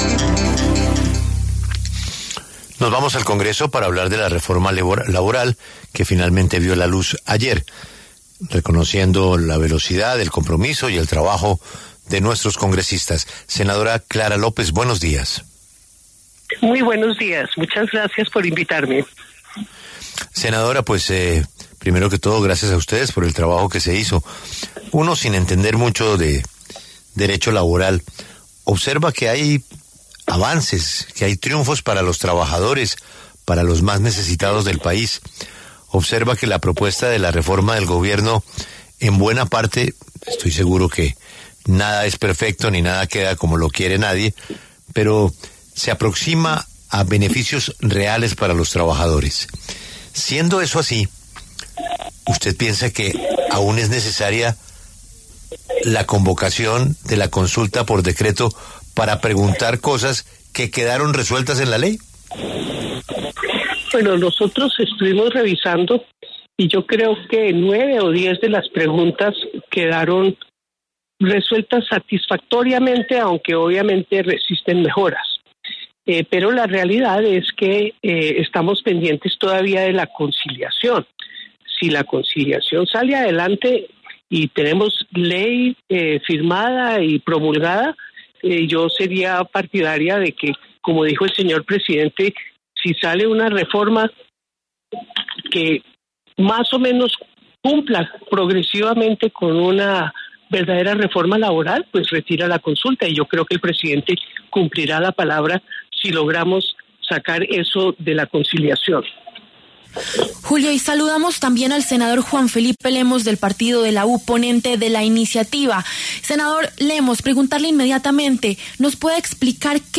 Los congresistas Clara López, del Pacto Histórico, y Juan Felipe Lemos, del Partido de la U, pasaron por los micrófonos de La W.